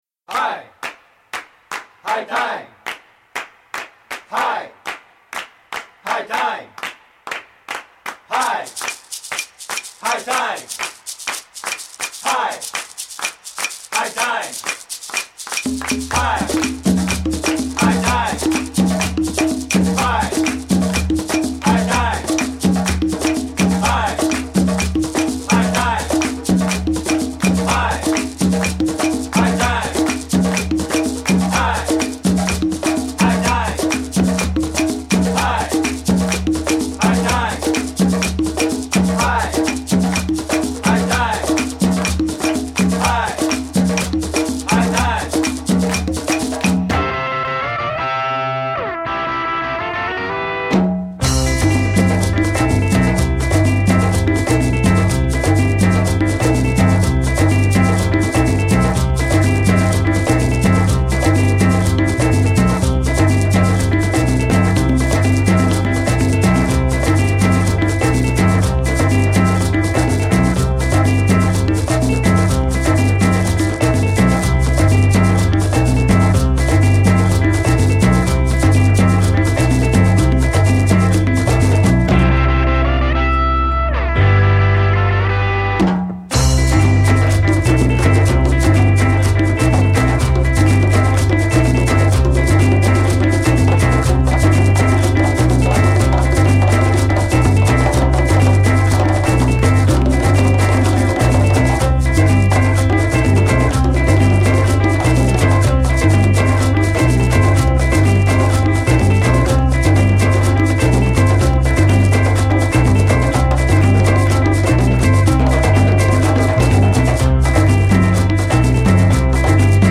疾走感溢れるリズムに乗せ繰り返す中毒的リフでグイグイとハメられるアフロファンク
オリエンタルなリード・ギターにグッと惹きつけられる、ダビーでロウな